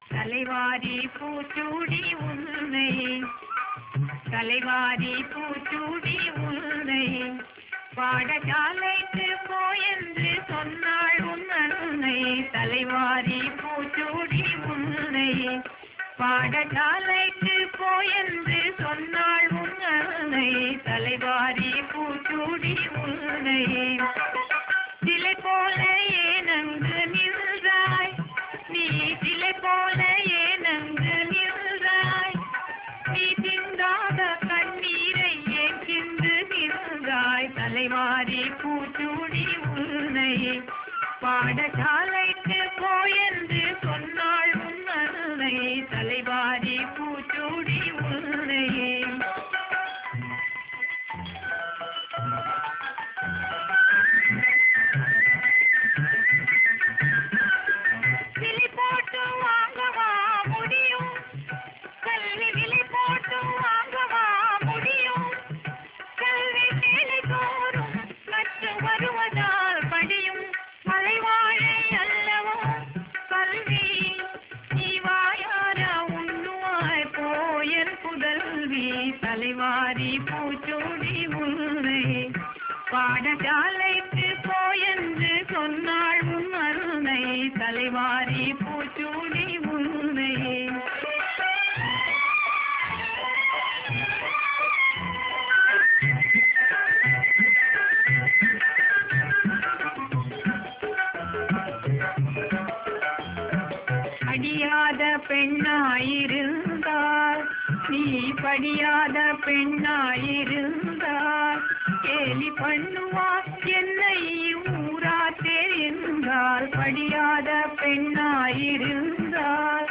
சிறுவர் பாடல்கள் | தமிழ் இணையக் கல்விக்கழகம் TAMIL VIRTUAL ACADEMY